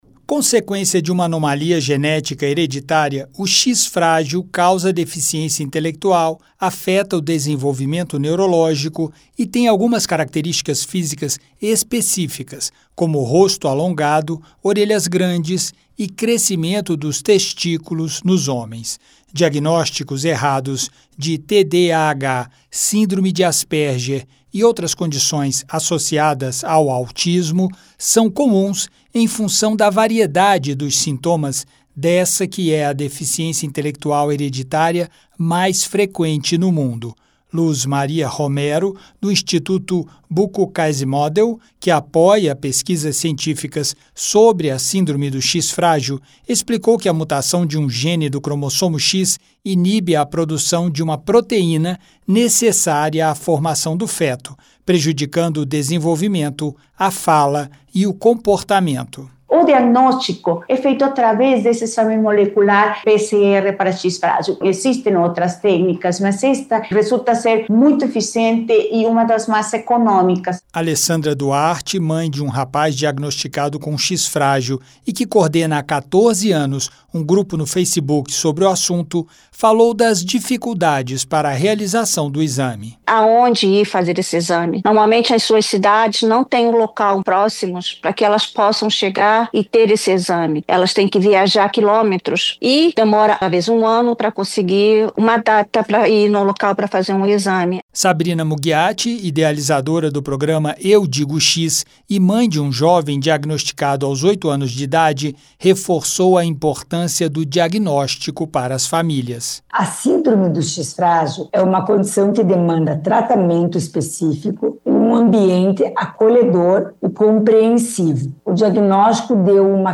Debatedores que participaram de audiência na Comissão de Direitos Humanos (CDH) nesta segunda-feira (22) defenderam a criação do Dia Nacional de Conscientização do X Frágil, a ser celebrado em 22 de julho. Anomalia genética hereditária, o 'X frágil' causa deficiência intelectual e afeta o desenvolvimento neurológico.